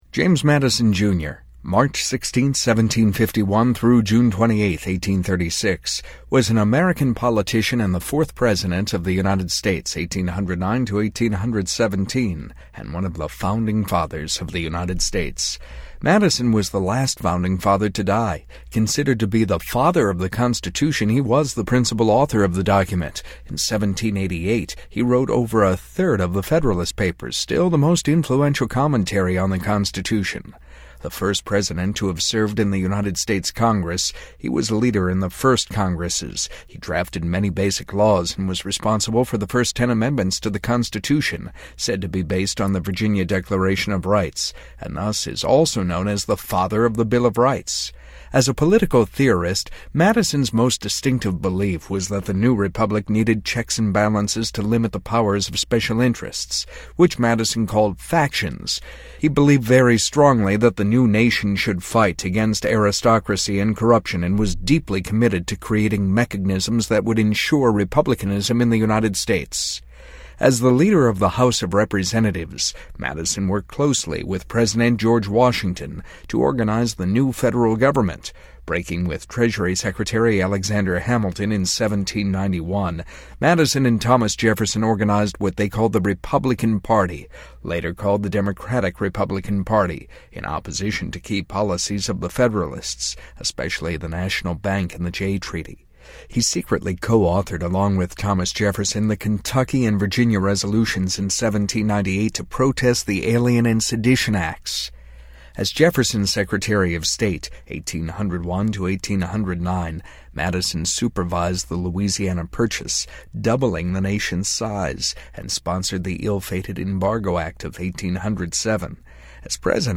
Selected Excerpts from Spoken Wikipedia